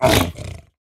Minecraft Version Minecraft Version snapshot Latest Release | Latest Snapshot snapshot / assets / minecraft / sounds / mob / piglin_brute / hurt4.ogg Compare With Compare With Latest Release | Latest Snapshot
hurt4.ogg